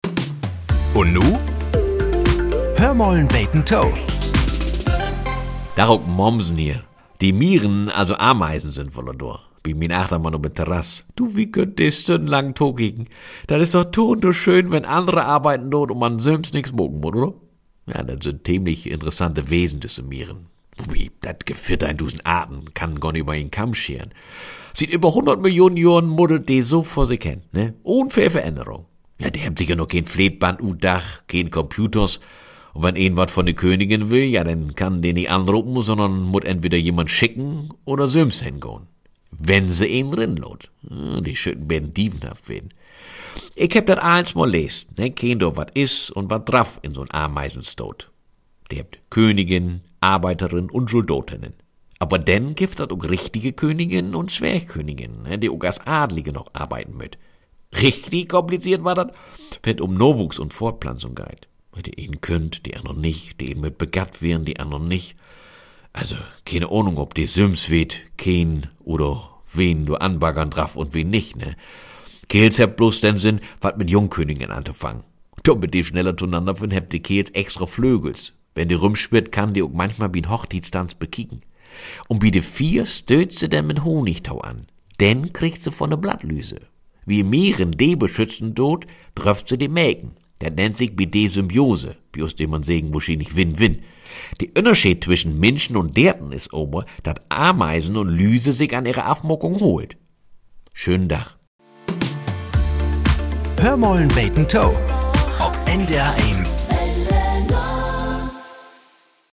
Die plattdeutsche Morgenplauderei "Hör mal 'n beten to" gehört seit mehr als 60 Jahren zum Alltag in Norddeutschland. Hier werden die Wunderlichkeiten des Alltags betrachtet. So klingt es, wenn wir Norddeutschen uns selbst auf die Schippe nehmen - liebevoll bis spöttisch, selten mit dem Finger in schmerzenden Wunden, aber immer an Stellen, an denen wir kitzelig sind.